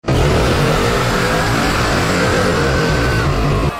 VOLUME WARNING!!!